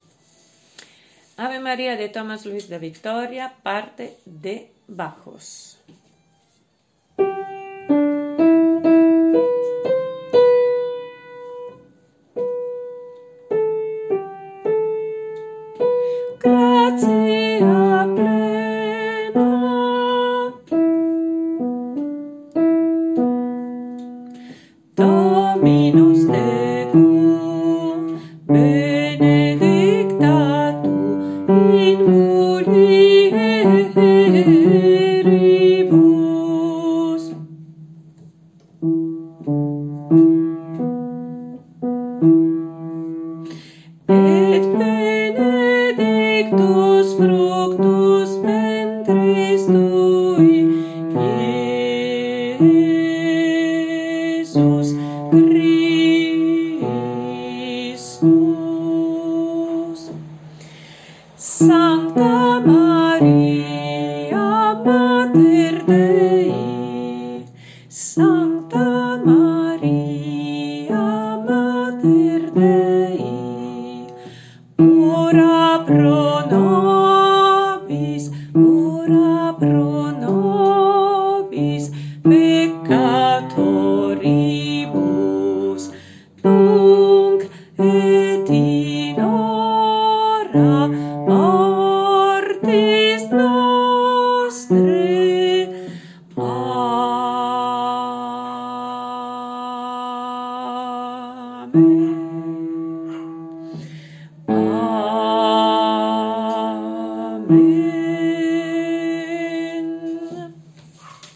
BAJO